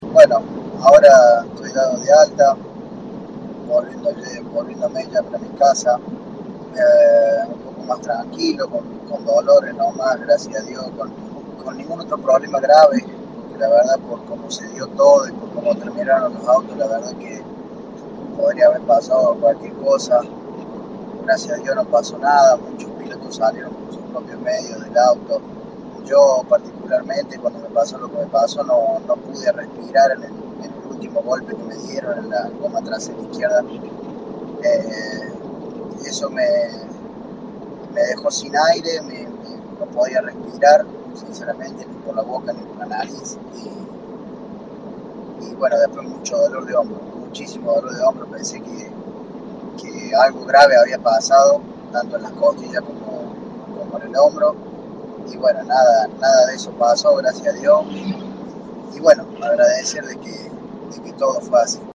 En diálogo con Poleman Radio